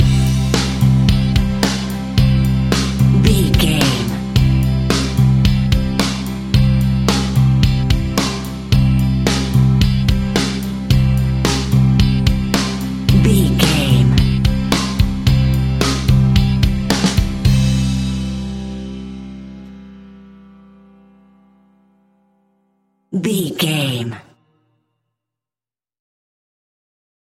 Ionian/Major
calm
happy
smooth
uplifting
electric guitar
bass guitar
drums
pop rock
indie pop
instrumentals
organ